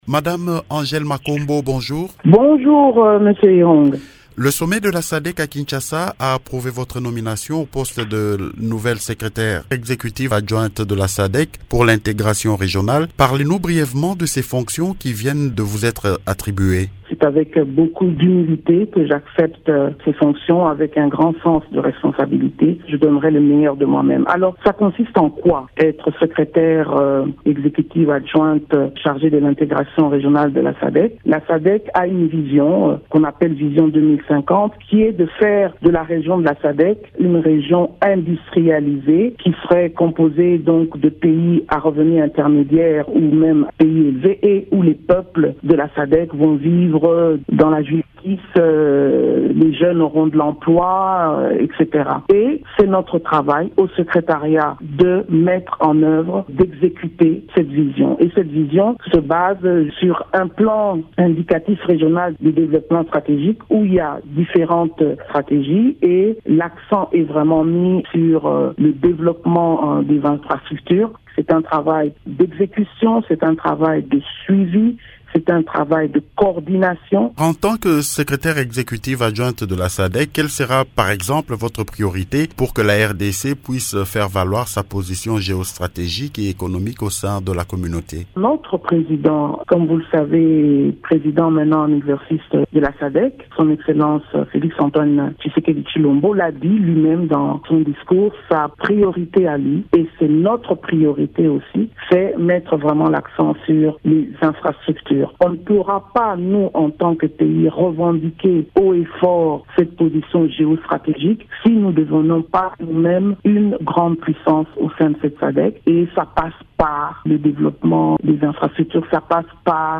Elle l’a dit au cours d’une interview accordée à Radio Okapi.